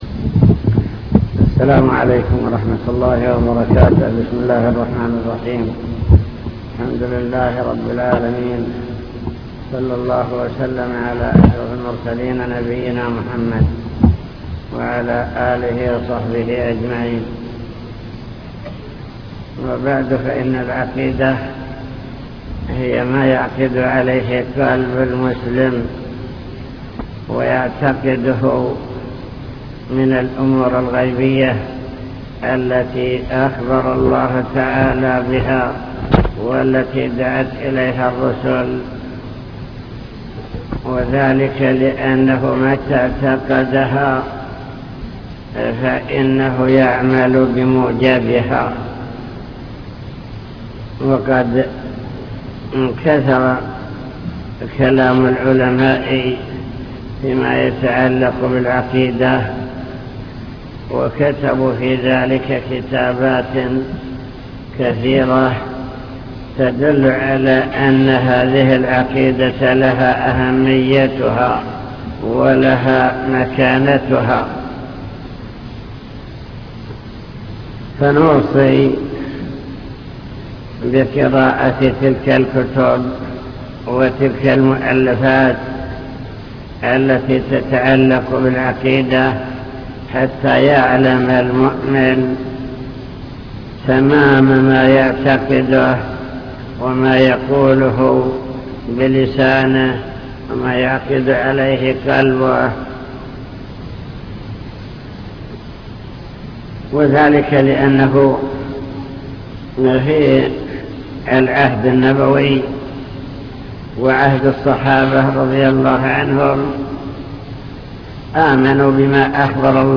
المكتبة الصوتية  تسجيلات - محاضرات ودروس  قوادح في العقيدة